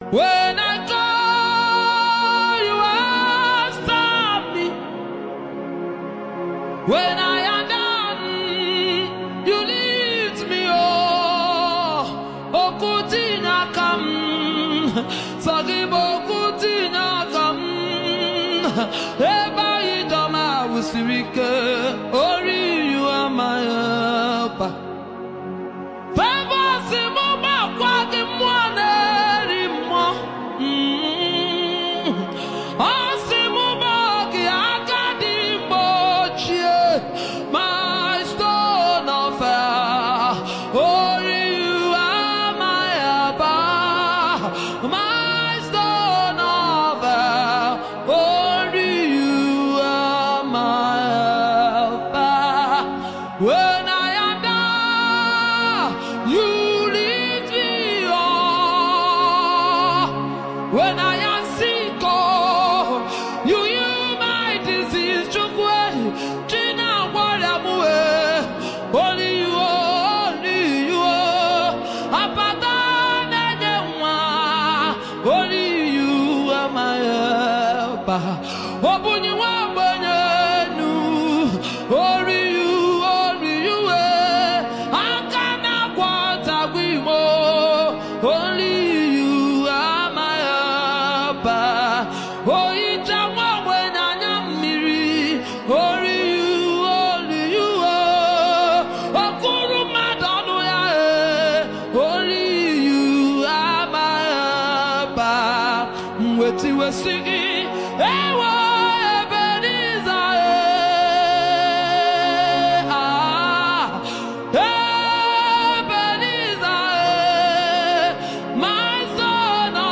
catchy track